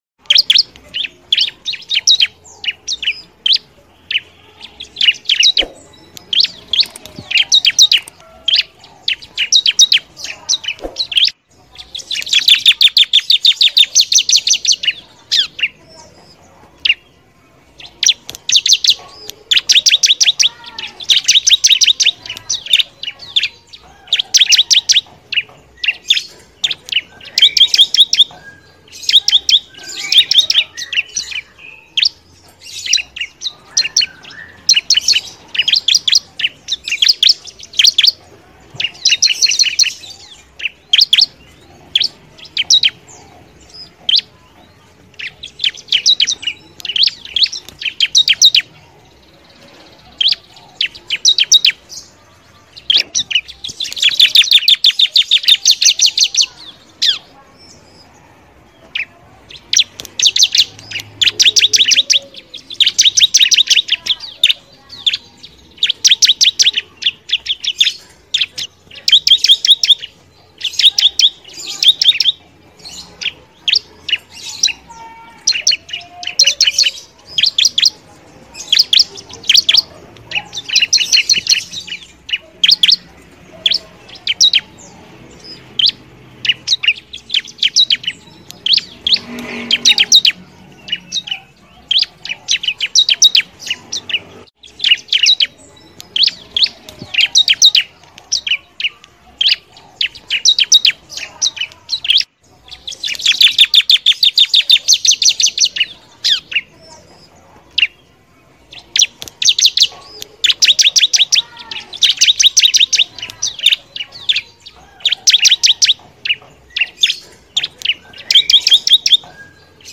Suara Burung Ciblek
Kategori: Suara burung
suara-burung-ciblek-id-www_tiengdong_com.mp3